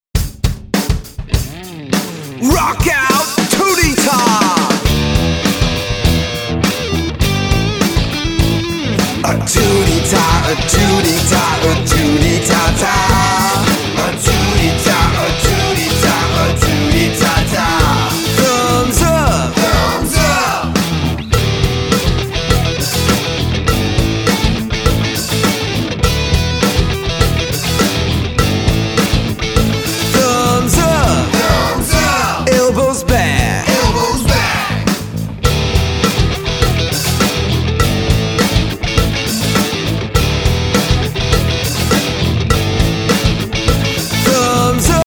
Rock out with this favorite movement song.
Listen to the open version of this song.